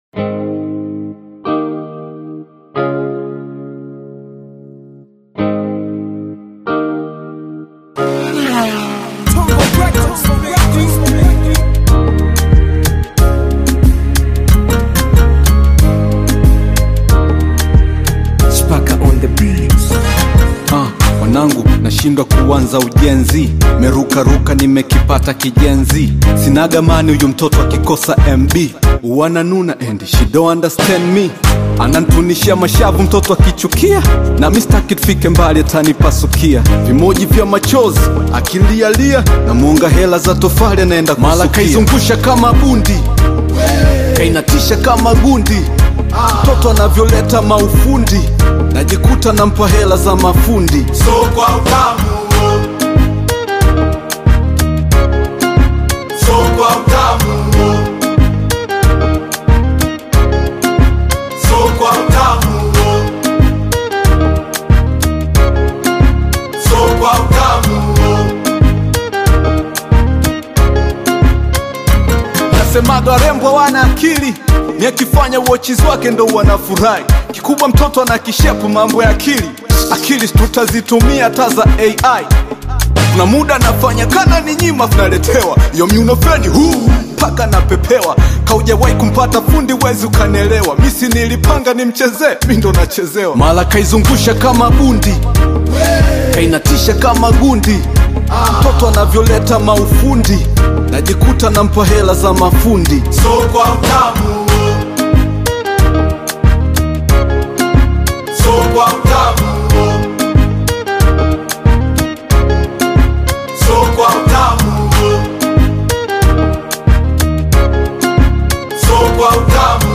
bold Tanzanian Hip-Hop/Gengetone-influenced single